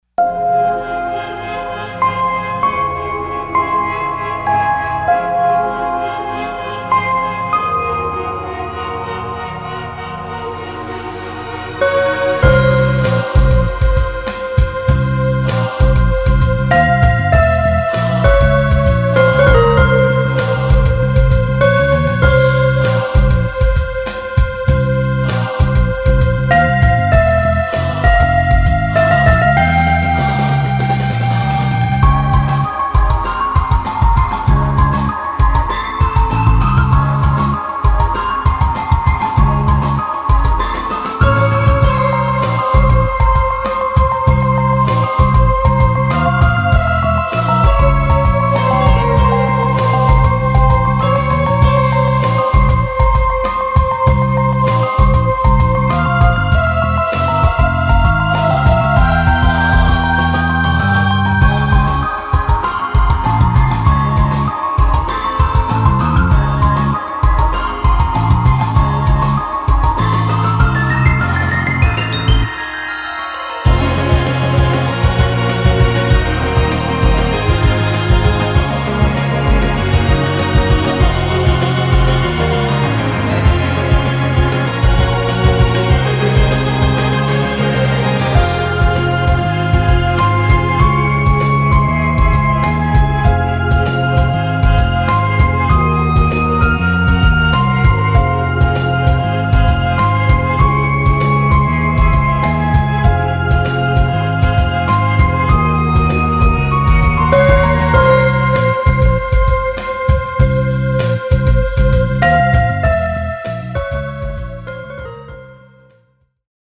フリーBGM イベントシーン ホラー・不気味・不穏
フェードアウト版のmp3を、こちらのページにて無料で配布しています。